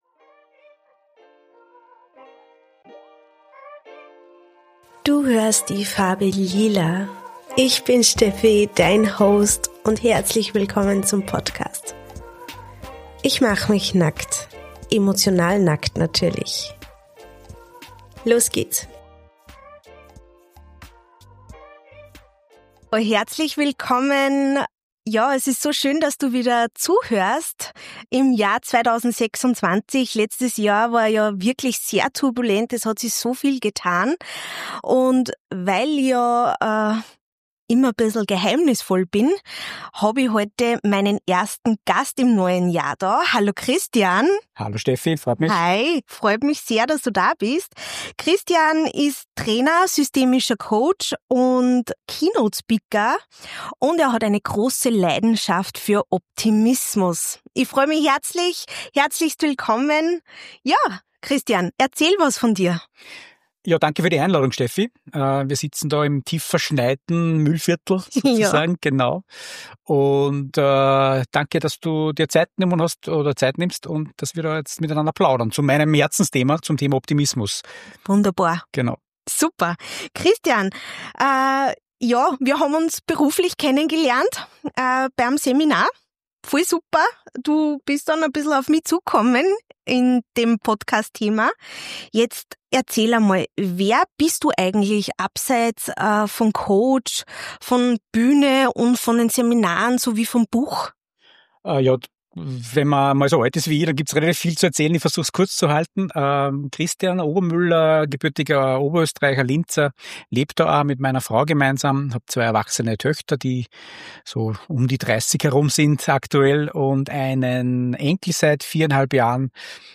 Diese Folge ist ein ehrliches Gespräch über Denken, Fühlen, Handeln und darüber, wie man auch im Regen seinen eigenen Rhythmus findet.